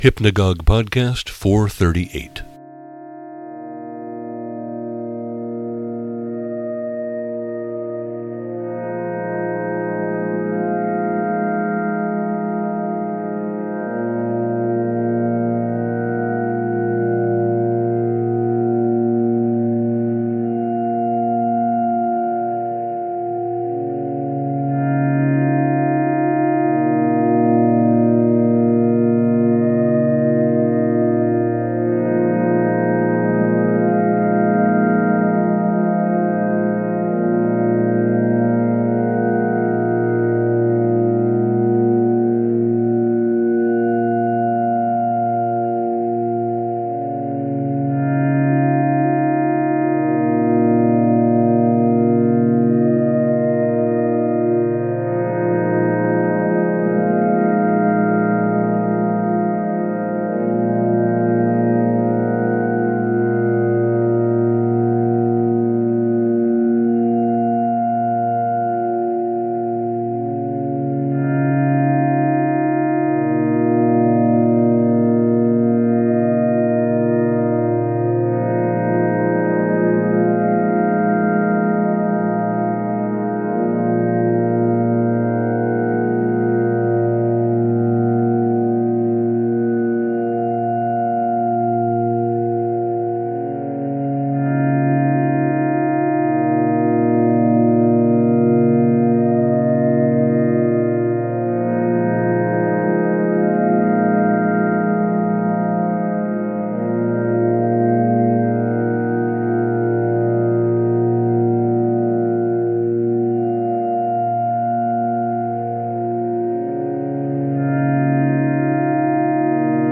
Lush sounds.